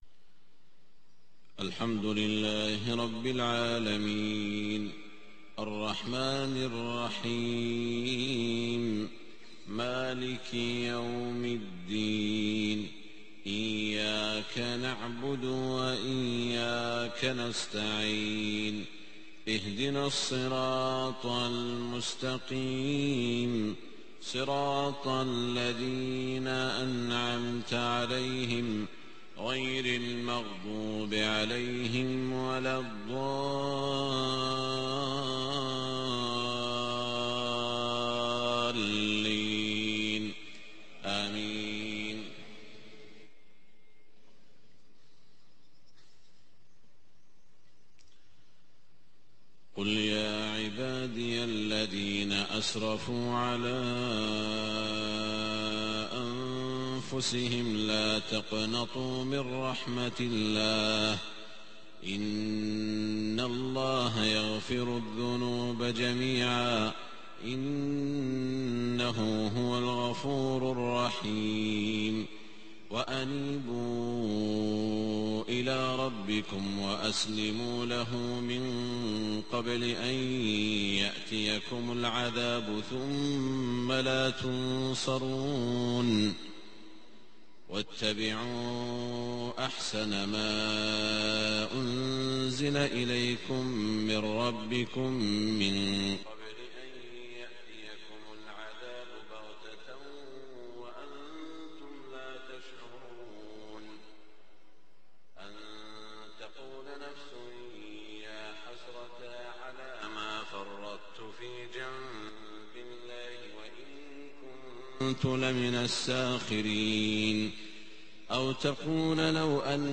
صلاة الفجر 3-2-1428هـ من سورة الزمر > 1428 🕋 > الفروض - تلاوات الحرمين